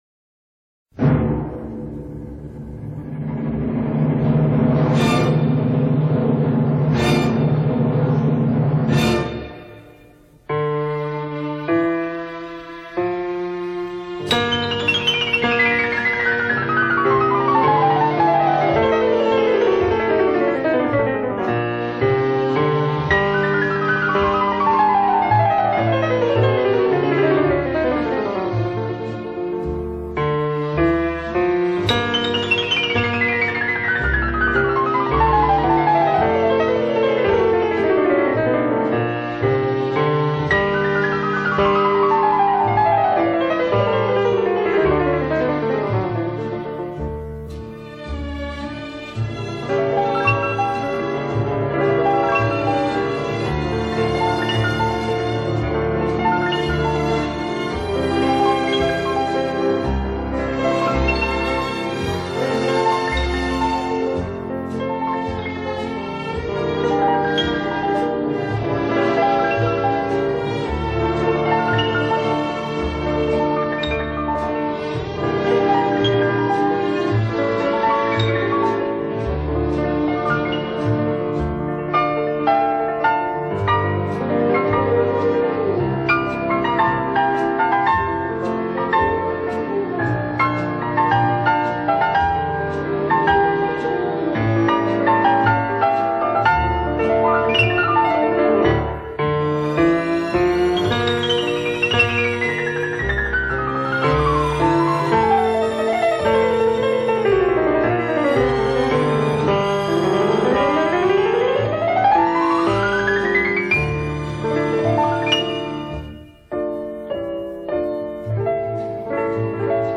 01 钢琴